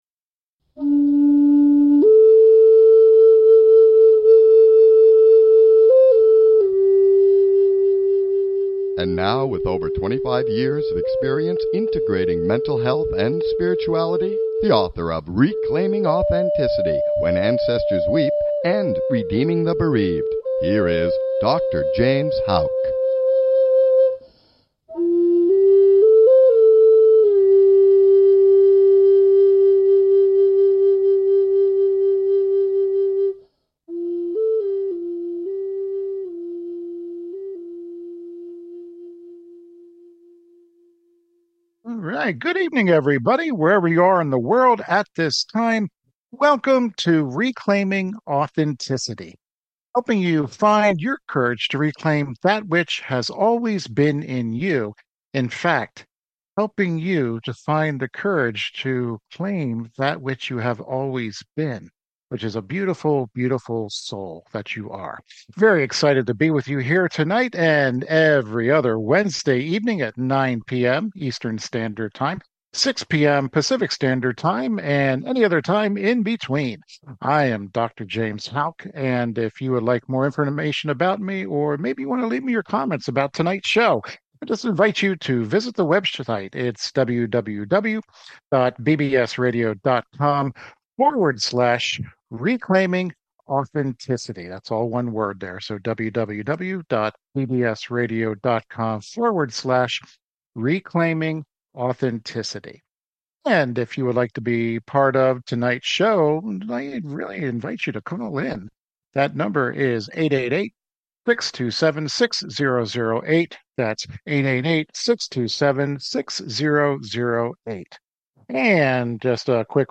Talk Show Episode, Audio Podcast, Reclaiming Authenticity and The Combined themes of LIGHT AND HOPE!